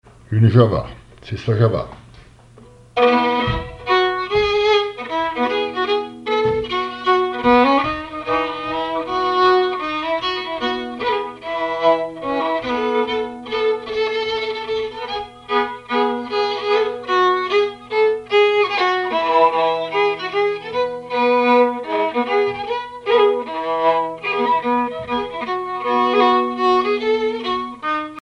Mémoires et Patrimoines vivants - RaddO est une base de données d'archives iconographiques et sonores.
violoneux, violon
danse : java
Pièce musicale inédite